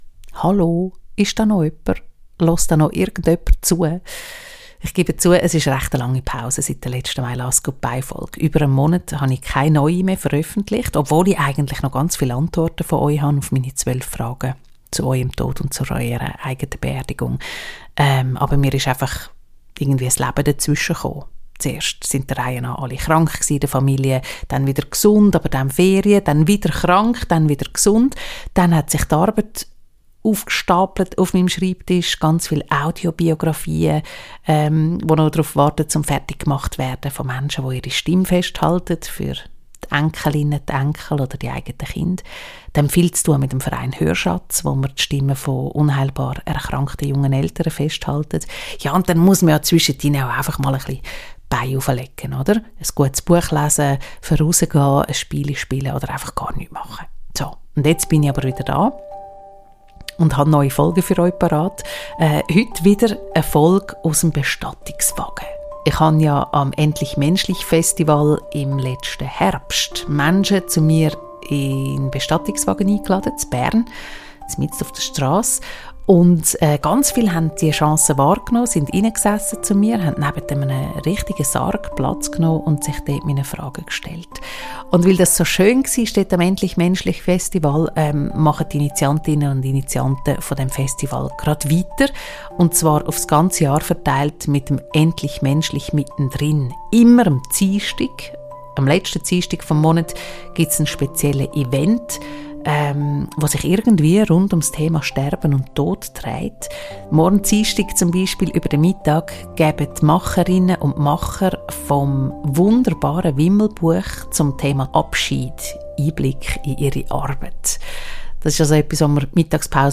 Dafür melde ich mich jetzt mit einem ganz wunderbar entspannten, intimen Gespräch aus dem Bestattungswagen zurück.